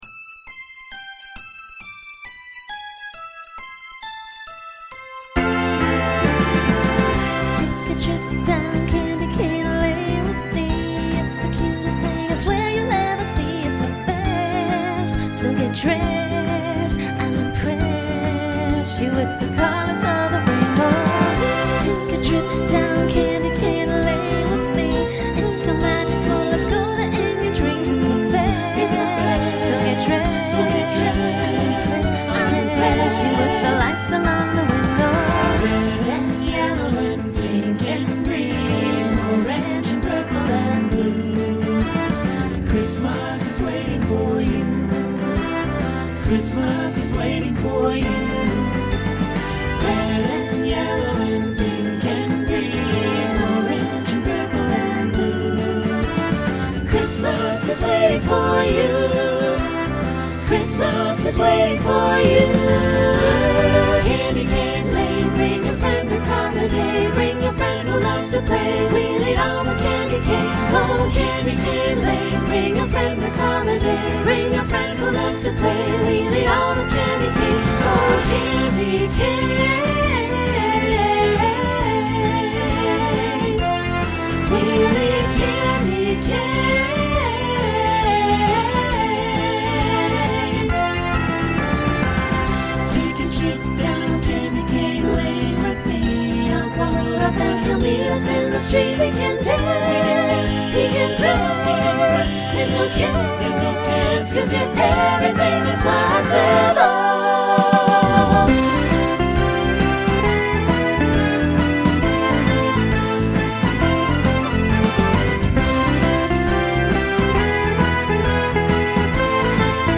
Genre Holiday , Pop/Dance Instrumental combo
Mid-tempo , Opener Voicing SATB